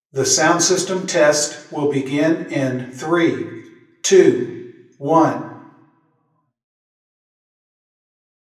The following equipment was used for the collection of room impulse responses using log sine sweeps.
Microphone: Sennheiser Ambeo
Our training seminar was held in the Great Room, a large, flat-ceiling meeting space with acoustical tile ceiling and carpet. The parallel walls are covered with large paintings, some of which have significant absorption. Here are the measures collected 40 ft from an omni source (TP2) post-processed to XYStereo.
The Great Room has some audible flutter.